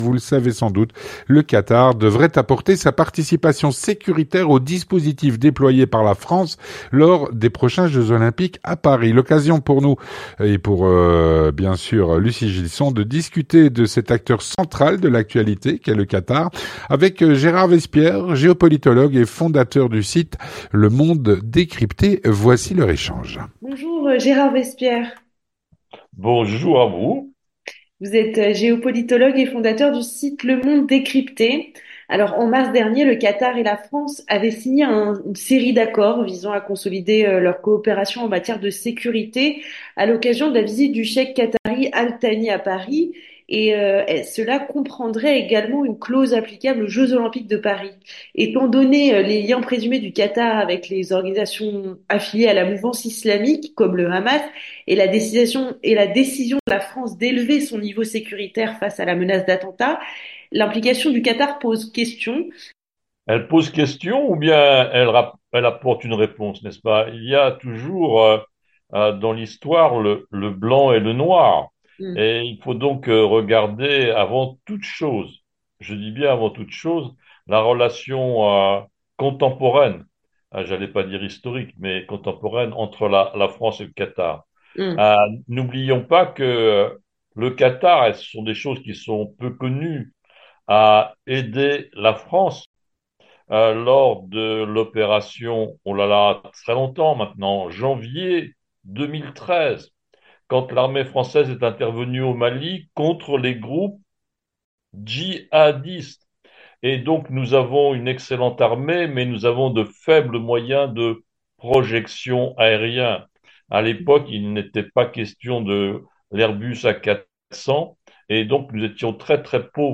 L'entretien du 18H - Le Qatar devrait apporter sa participation sécuritaire au dispositif déployé par la France au JO de Paris.